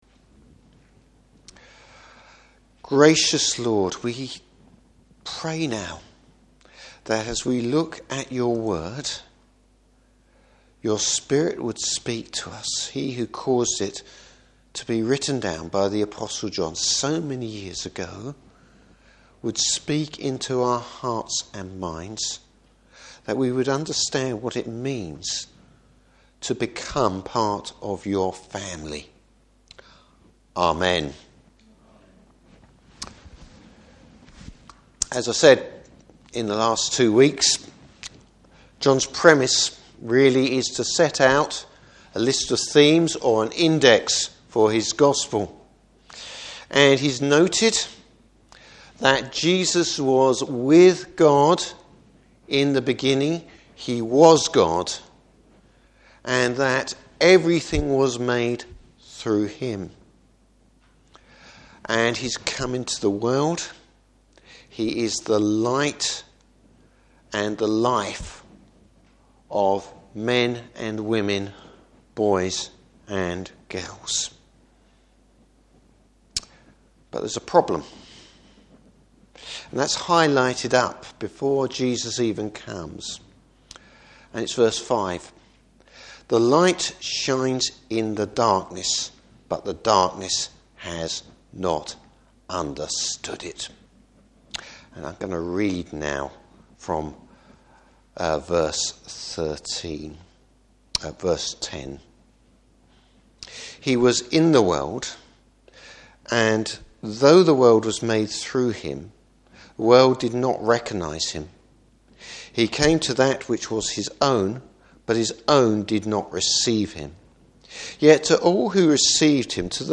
Service Type: Morning Service Becoming children of God.